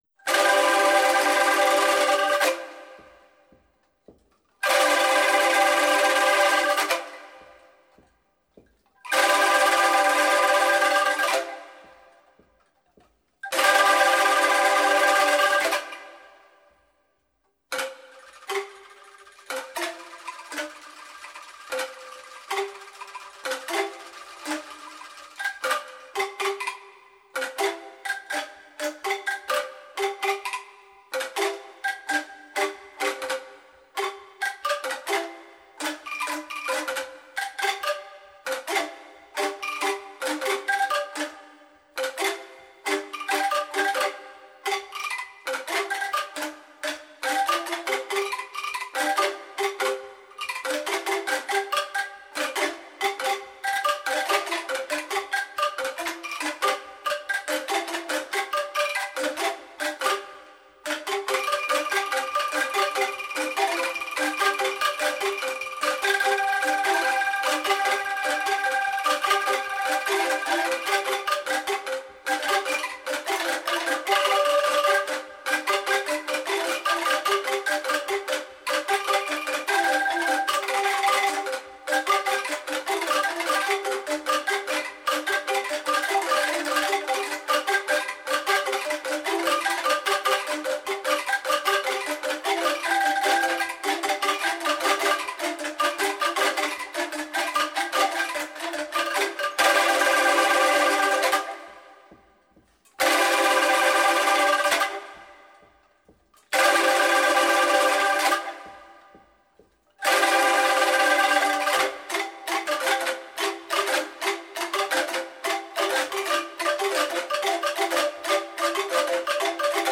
soprano and tenor saxophones
bass, synth programs, percussion
guitar, voices